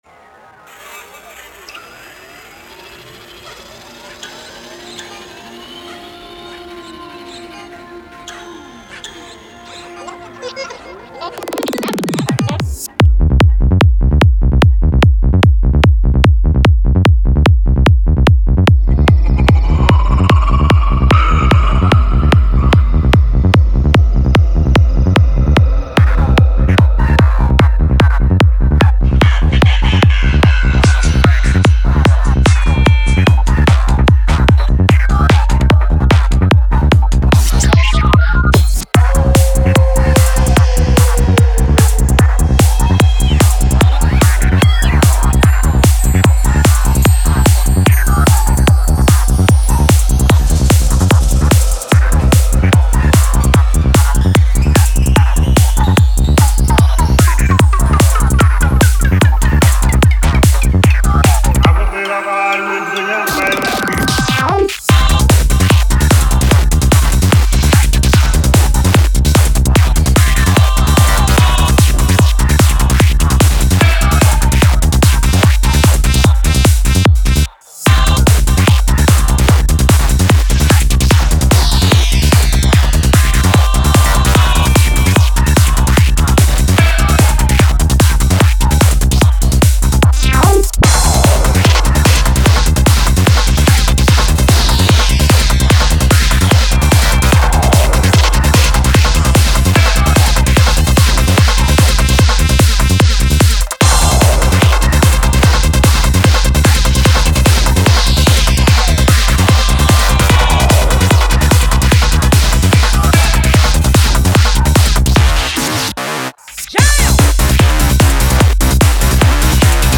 Genre: Psychedelic Trance
(148 BPM)